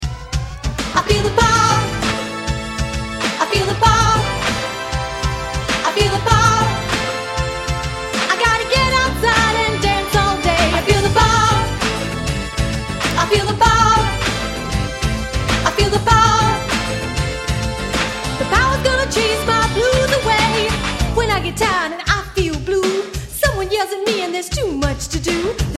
A Nature Appreciation Song